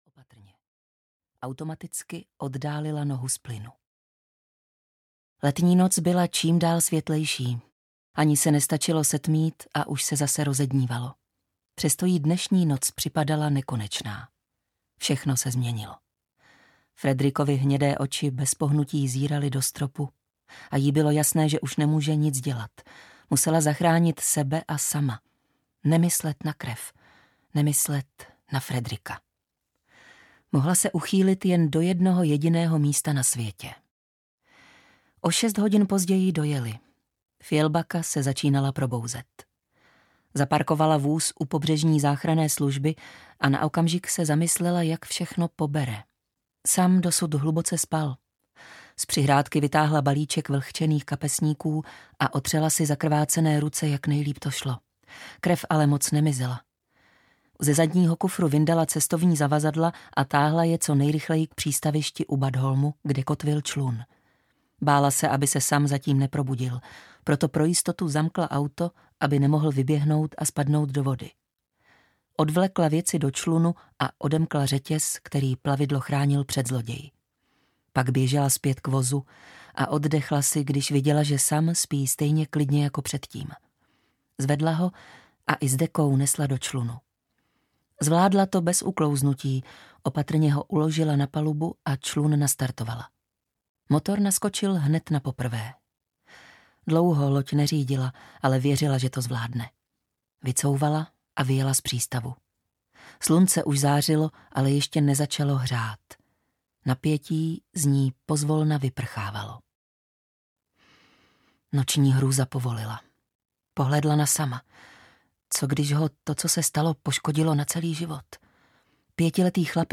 Strážce majáku audiokniha
Ukázka z knihy